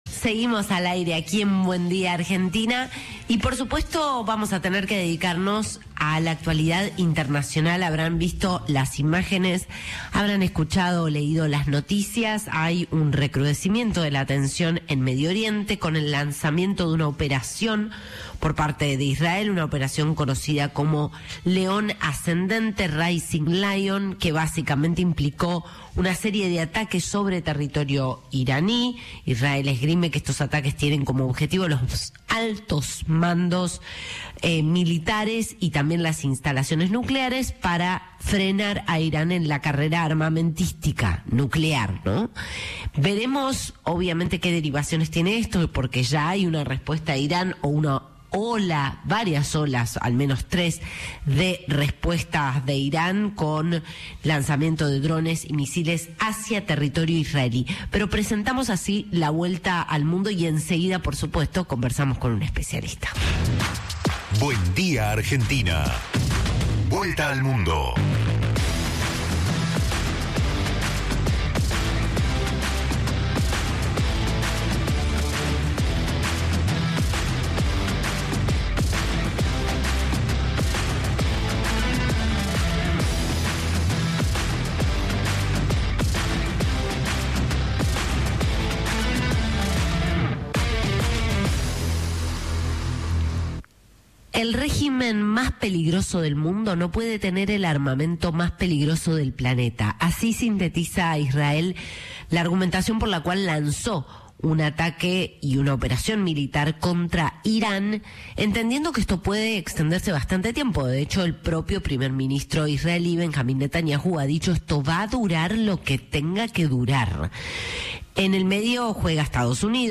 El ex ministro de Turismo de la Nación, Gustavo Santos, habló con Cadena 3 sobre cómo perjudica la decisión a la Argentina y alertó que es imposible desarrollar el país sin conectividad aérea.